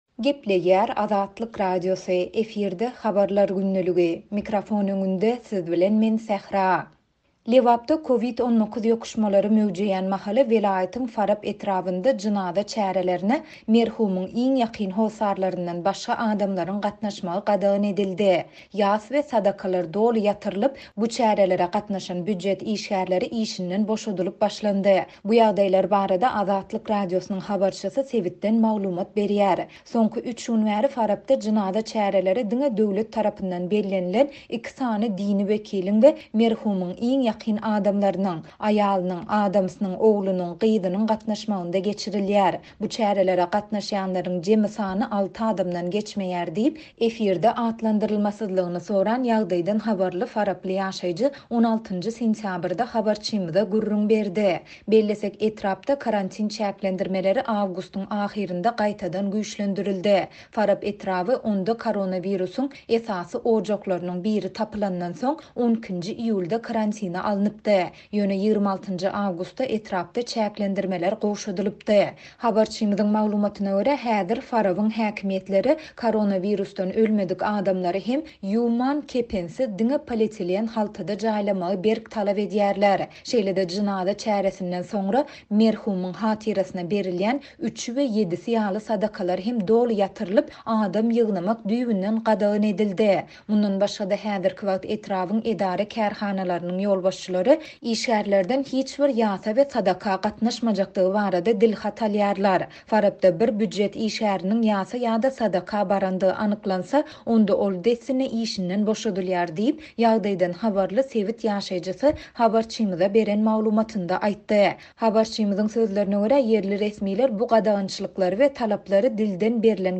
Bu ýagdaýlar barada Azatlyk Radiosynyň habarçysy sebitden maglumat berýär.